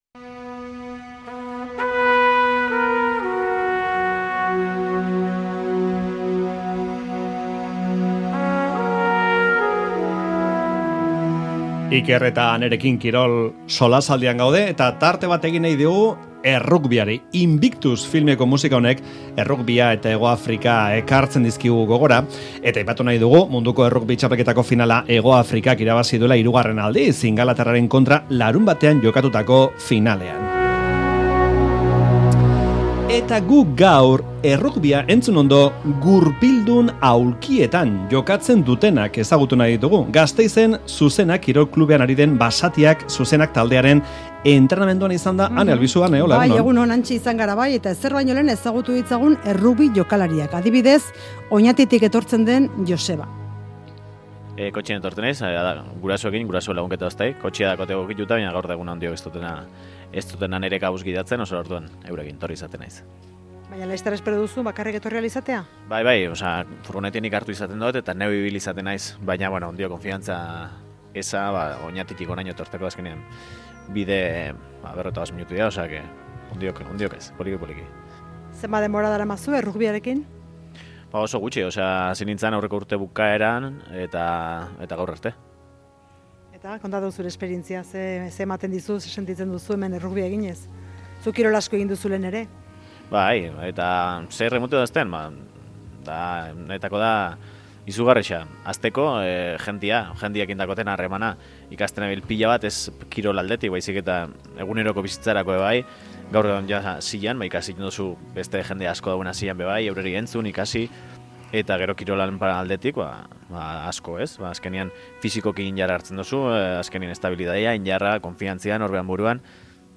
Audioa: 'Basatiak zuzenean', errugbi egokitua egiten duen Euskal Herriko talde bakarra ezagutzen izan gara euren entrenamandu saio batean Gasteizen